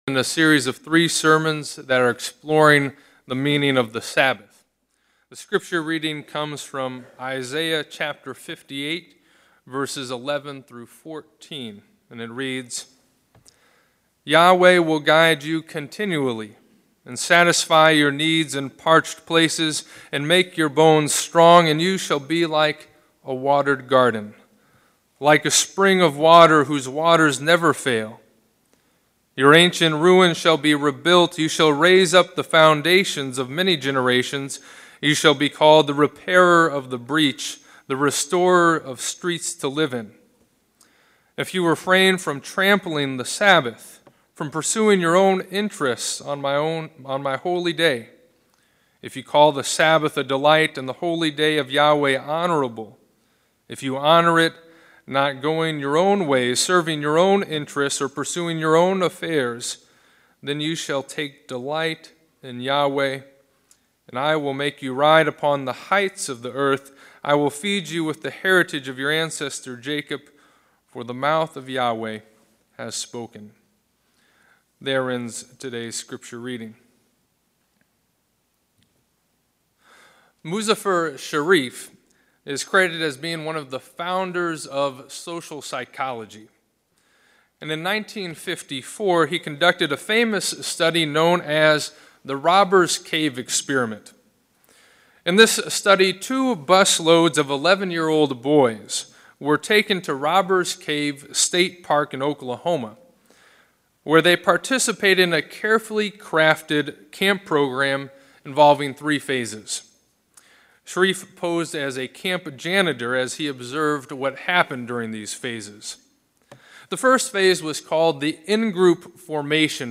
This sermon is the second in a series of sermons that explores the meaning of the Sabbath.